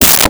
Space Gun 16
Space Gun 16.wav